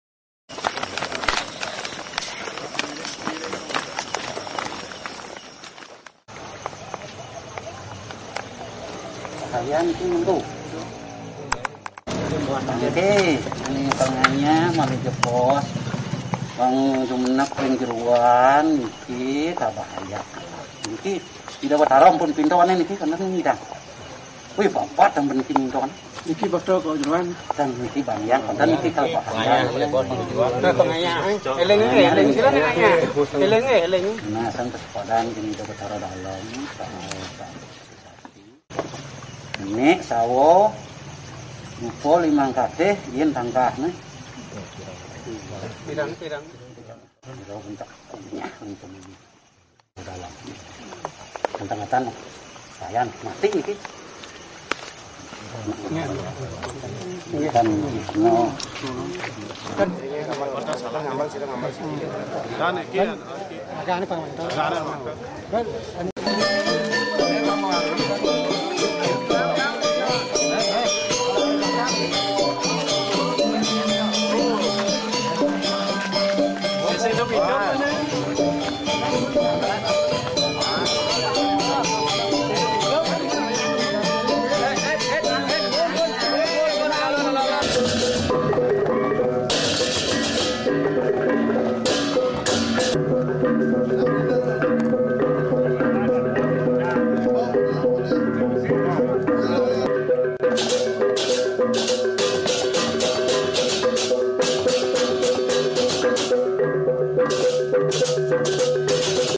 Rangda sakral bergelar Ratu Badung yang disucikan di Desa Peliatan, memberi peringatan kepada pemeran mayat-mayatan saat pementasan calonarang di Pura Dalem Kangin Tegalllalang .